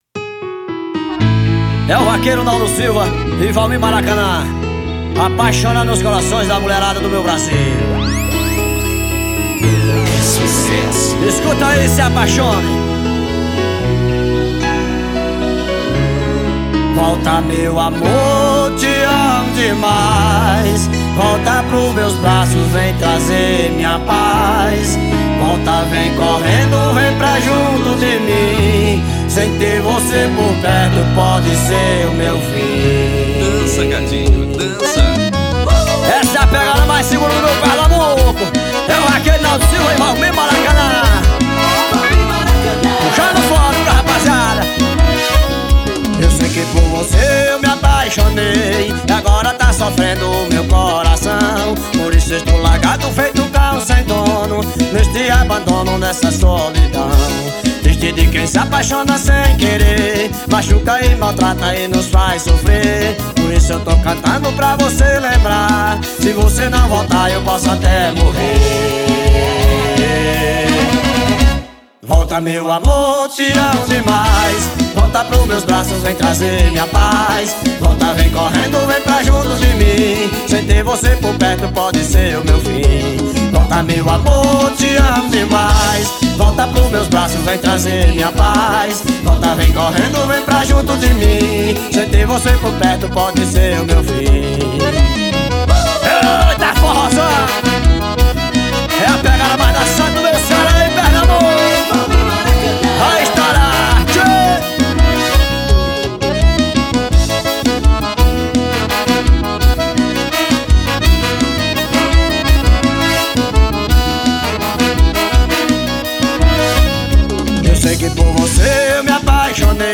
AO VIVO 2016.